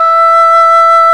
Index of /90_sSampleCDs/Roland LCDP04 Orchestral Winds/CMB_Wind Sects 1/CMB_Wind Sect 2
WND ENGHRN0F.wav